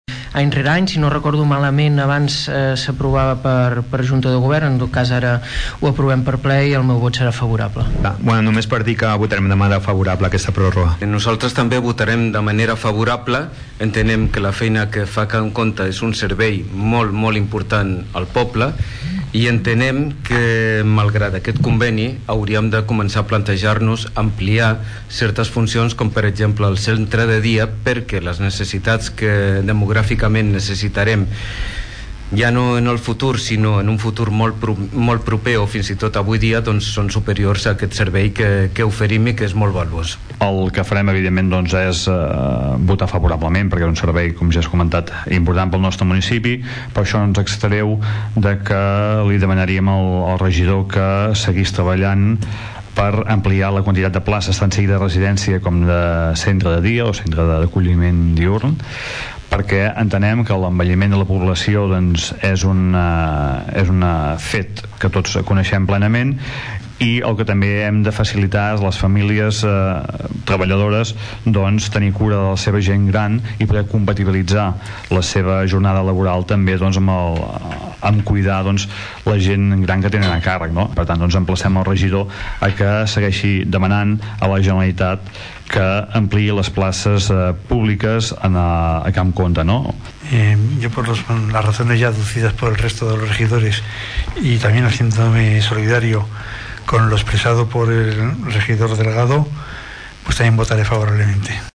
La resta de partits del plenari també van votar a favor de sol·licitar la renovació del conveni de la residència Can Comte per a l’any vinent. A banda, es va demanar una ampliació de places de cara al futur. Escoltem Xavier Martin del PP, Salvador Giralt de SOM Tordera, Rafa Delgado del PSC i Xavier Pla d’ERC+Gent de Tordera.